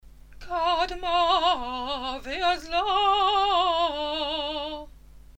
The sound of the Haftarot is the sound of forgiveness, even if the texts may be admonitions to call us to our tasks.
Qadma veAzla (Haftarah)